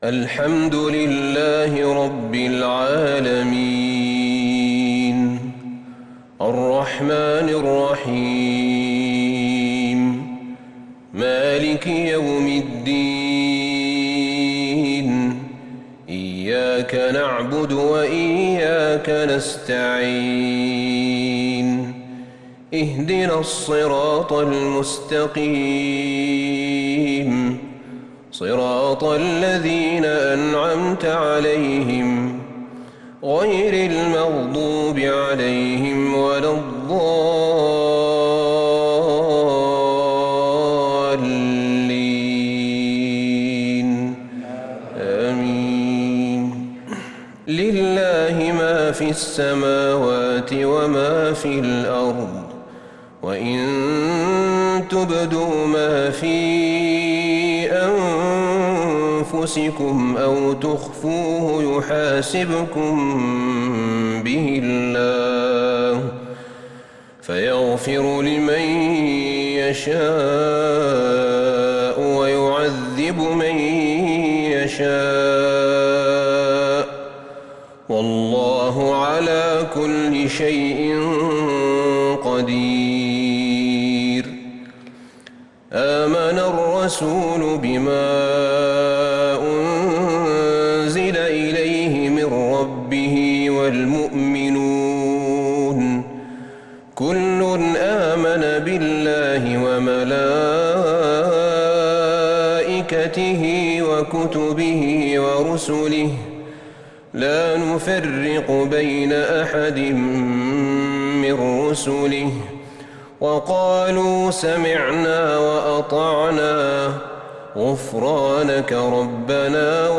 صلاة العشاء للشيخ أحمد بن طالب حميد 28 شوال 1442 هـ
تِلَاوَات الْحَرَمَيْن .